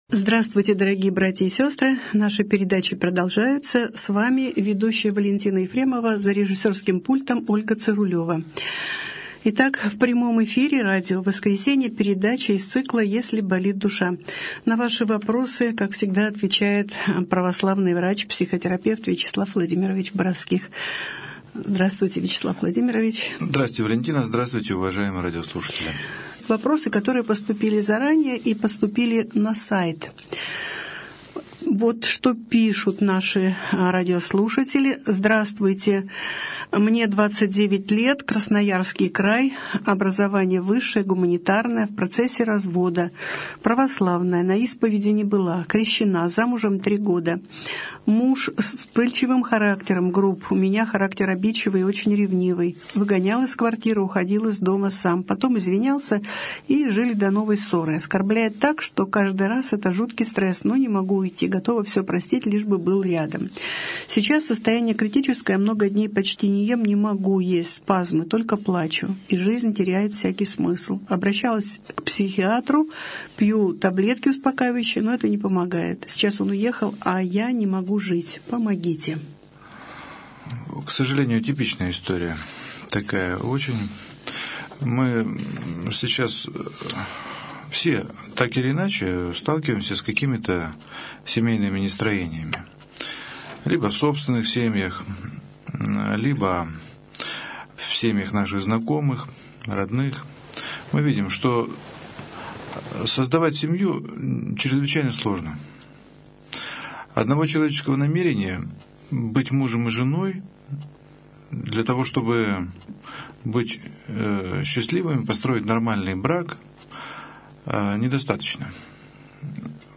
Беседа с психологом | Православное радио «Воскресение»